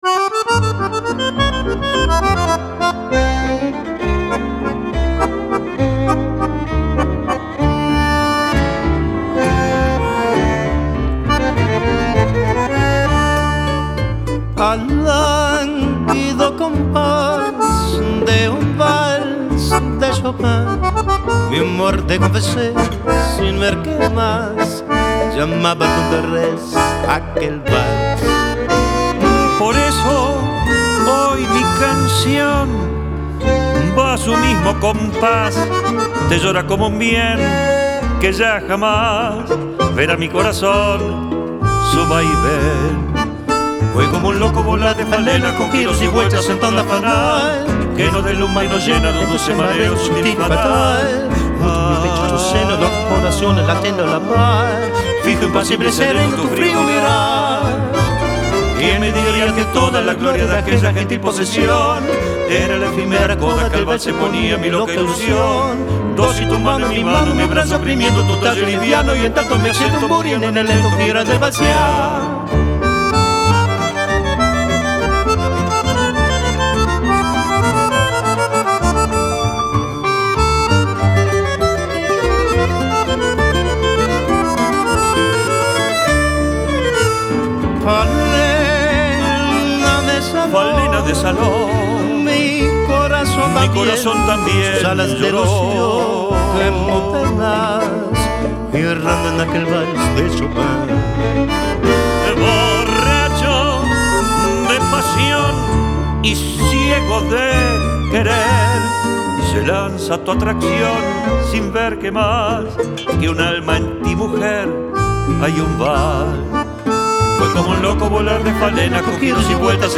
Tango zum "Vorschmecken"